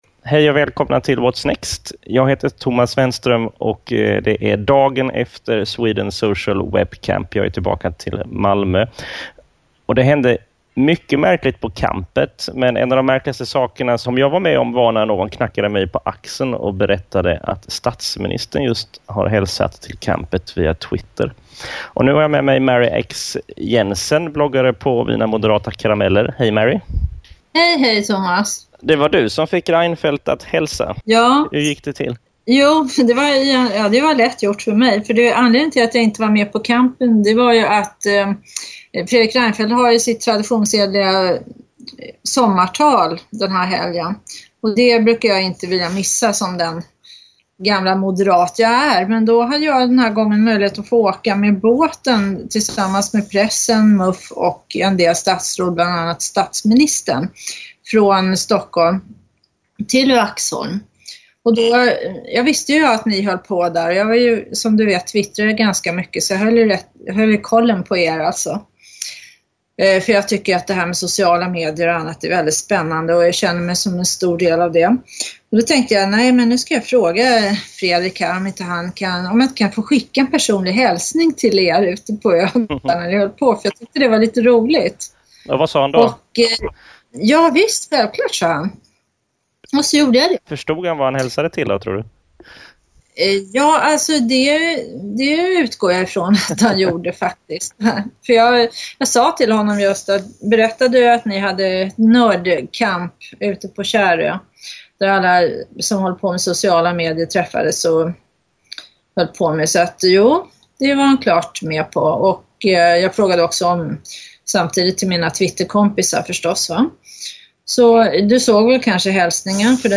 Intervjun hör du i denna podcast.